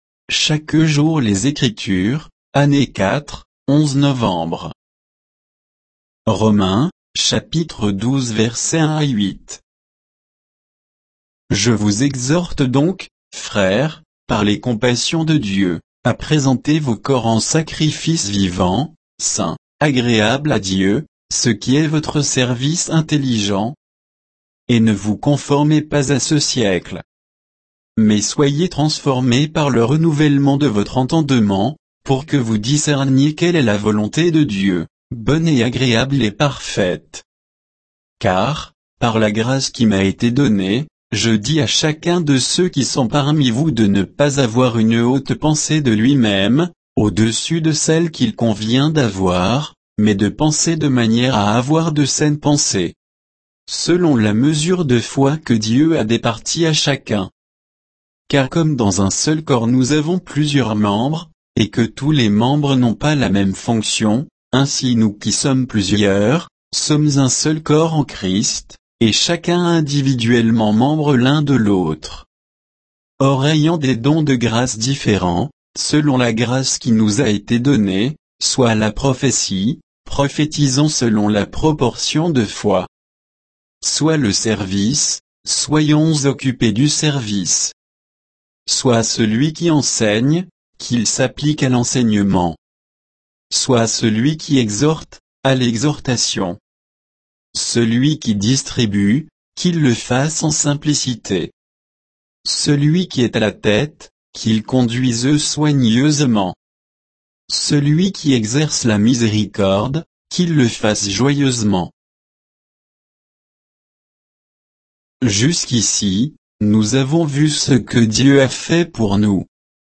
Méditation quoditienne de Chaque jour les Écritures sur Romains 12, 1 à 8